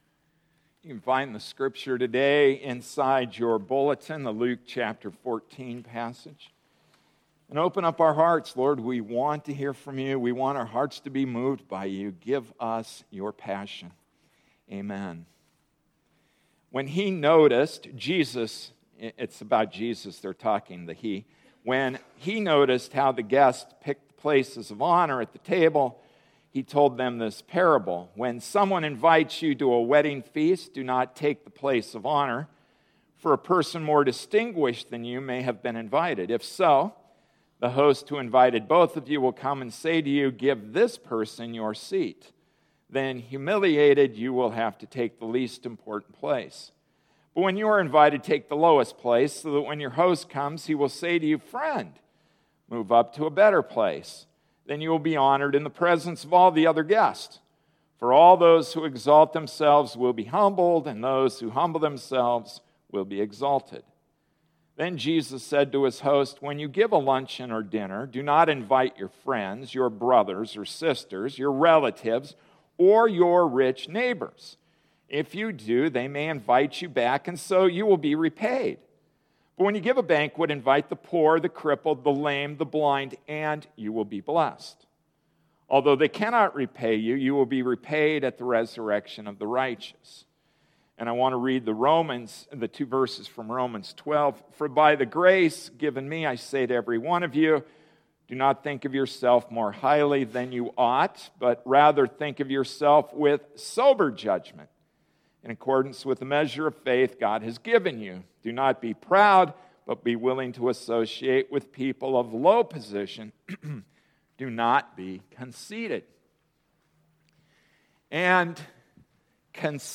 July 6, 2014 Conceit to Humility Passage: Luke 14:7-14, Romans 12:3, 12:16 Service Type: Sunday Morning Service “Conceit to Humility” Introduction: Of all our sins, conceit [pride, vanity, egotism] quite possibly disguises itself best.